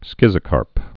(skĭzə-kärp, skĭtsə-)